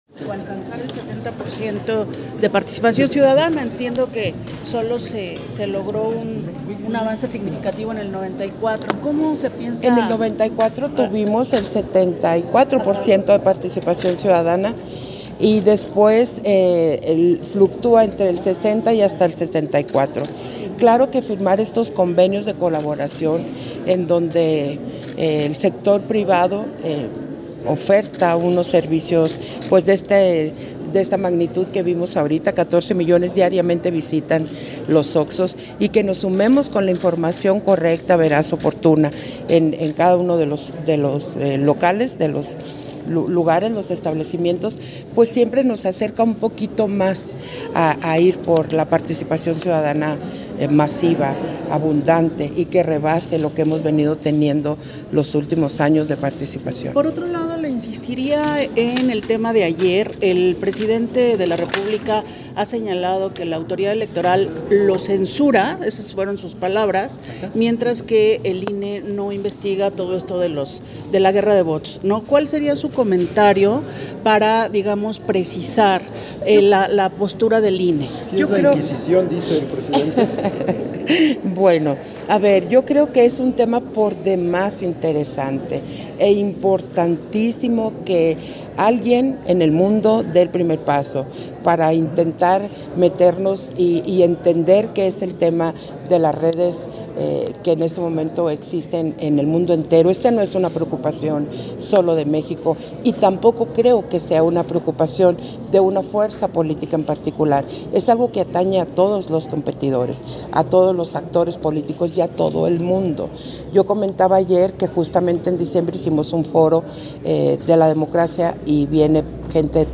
Entrevista de la Consejera Presidenta, Guadalupe Taddei, al concluir la firma de convenio de colaboración INE-FEMSA-OXXO
Versión estenográfica de la entrevista que Guadalupe Taddei, concedió a diversos medios de comunicación al concluir la firma de convenio de colaboración INE-FEMSA-OXXO